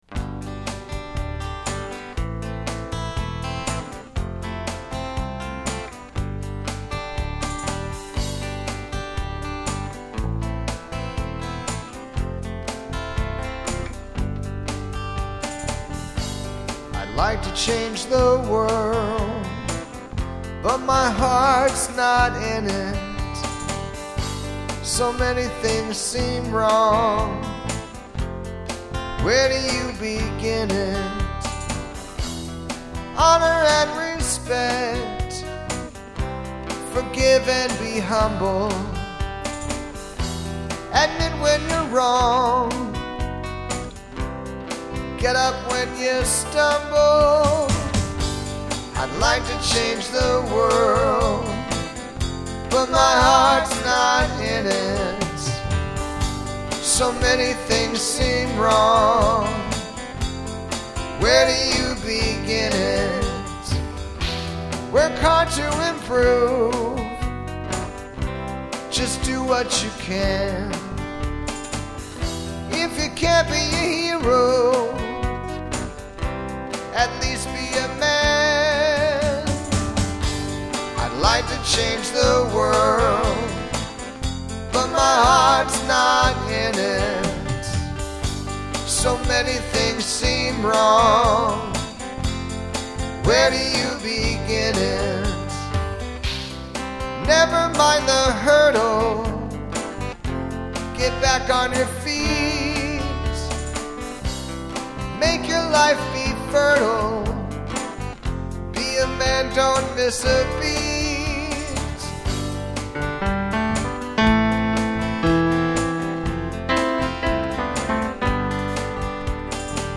120 b/m 3/2/20